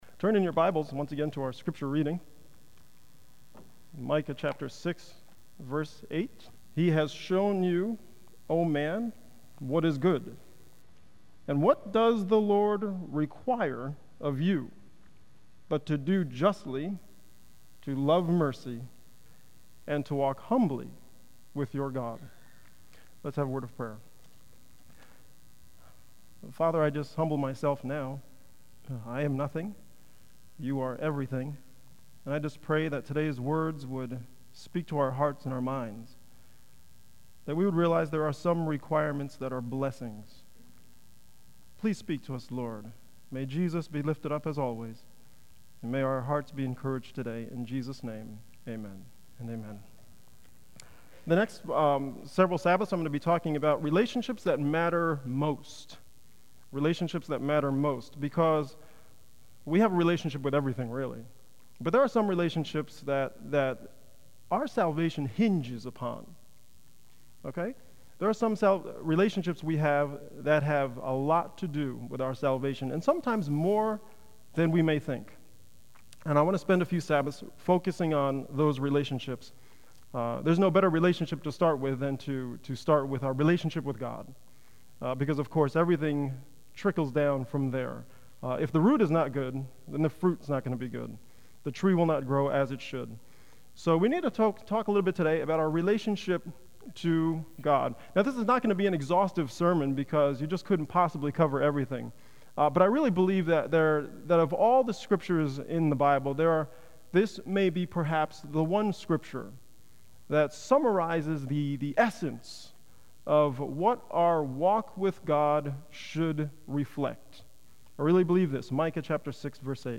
Sabbath Sermons